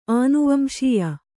♪ ānuvamśiya